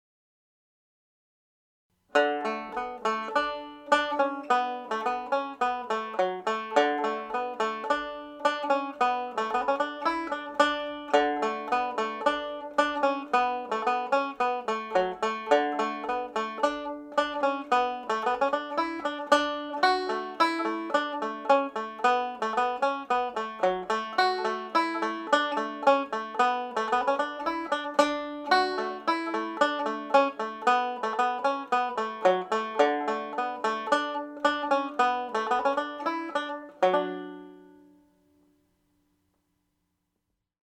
The polka is in the key of D major.
Denis Doody’s Polka played faster